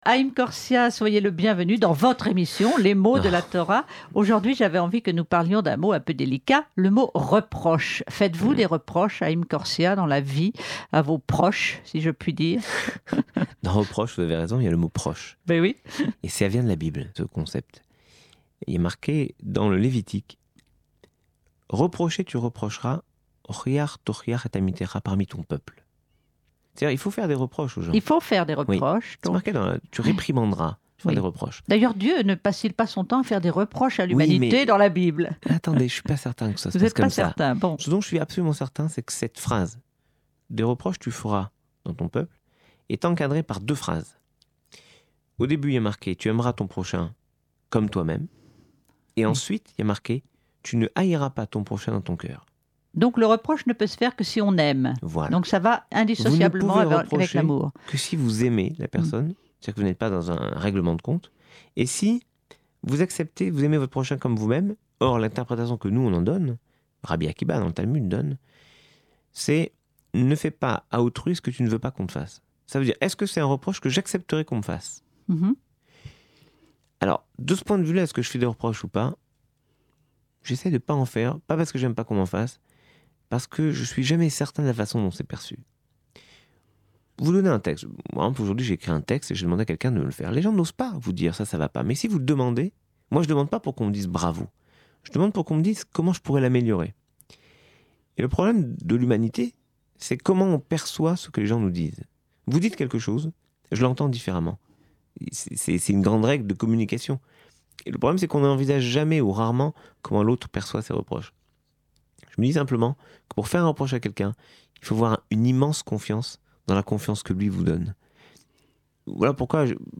Le Rabbin Haïm Korsia, Aumônier Général Israélite de l’armée de l’air, explique le sens du mot « reproche » dans la Torah.